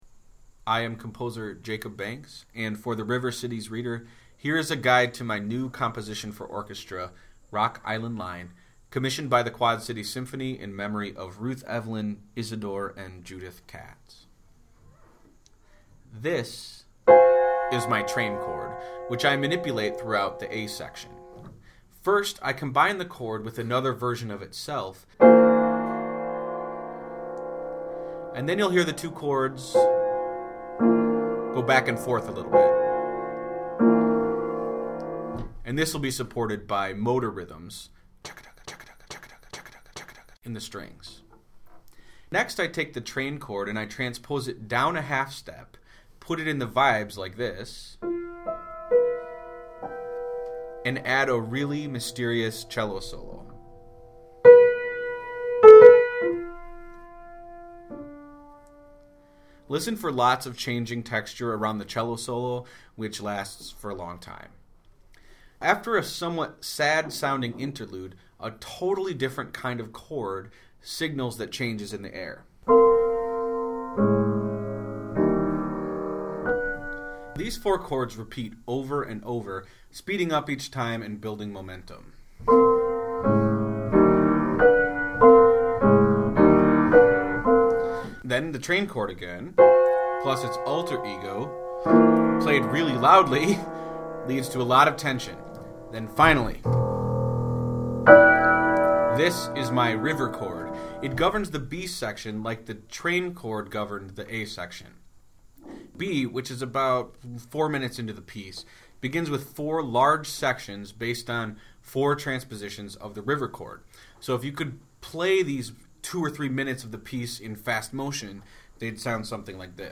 If the graph were played on a piano, he said, "it would sound like [the raw tonal frame of] the piece ...